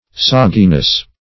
Search Result for " sogginess" : Wordnet 3.0 NOUN (1) 1. a heavy wetness ; The Collaborative International Dictionary of English v.0.48: Sogginess \Sog"gi*ness\, n. The quality or state of being soggy; soddenness; wetness.
sogginess.mp3